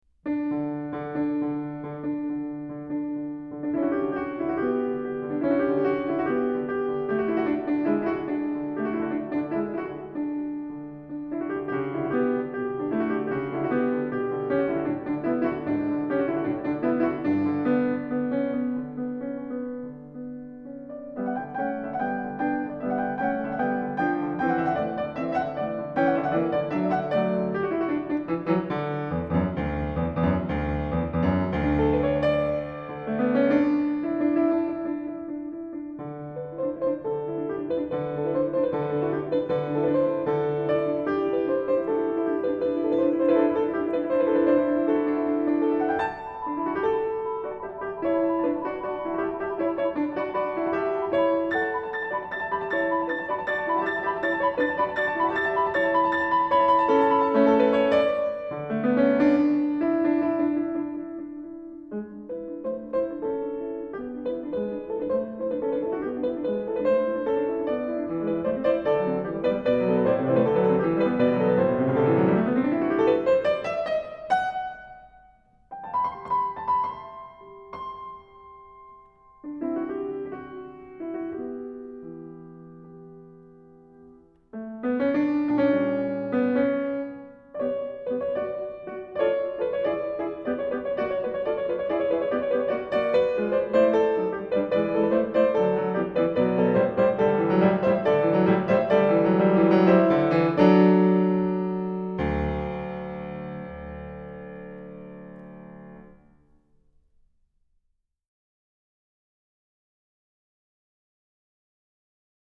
Sonatina para piano